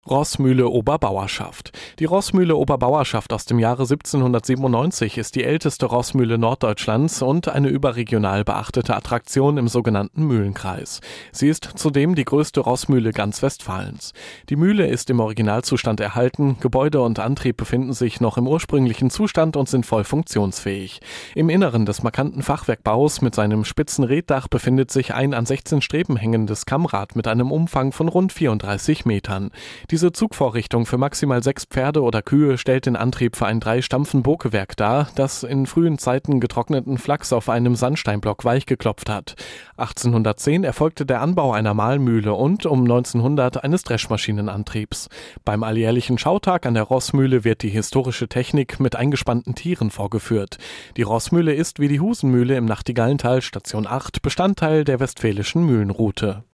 Audioguide Bauernbad-Radelroute Bünde
Mit dem Audioguide zur Bauernbad-Themenroute in Bünde erhalten Sie gesprochene Informationen zu den Besonderheiten entlang der Route.